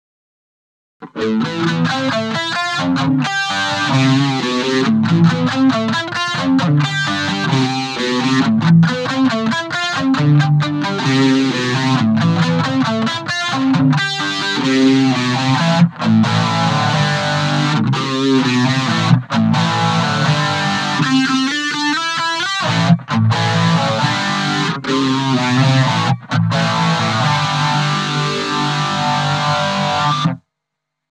avec mon phaser 90 modifié.
En gros on a l'intensity et le speed à 10h